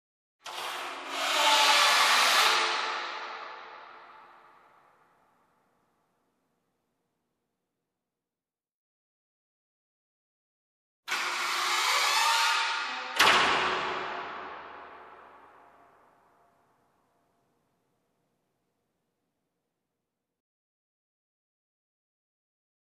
PORTE, COURT DE SQUASH
• Catégorie: Bruits de porte